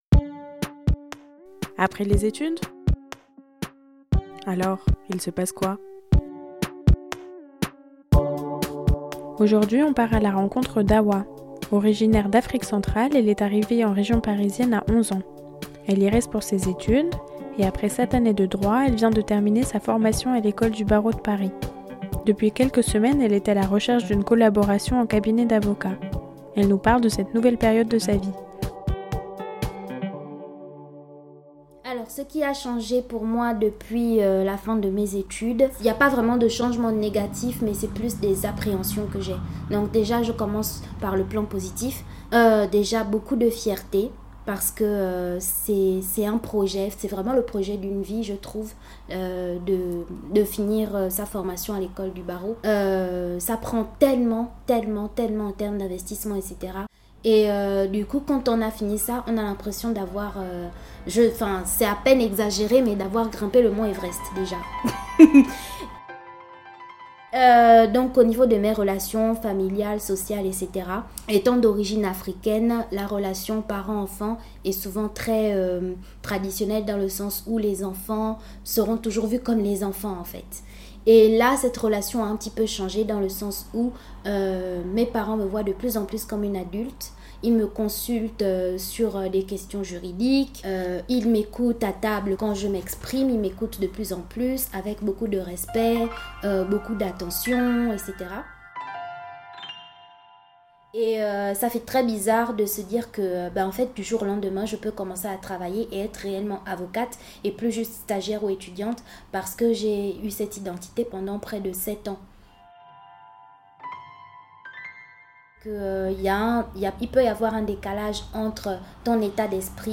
Le mois prochain, on continue l’exploration de cet entre-deux-mondes avec un nouveau témoignage.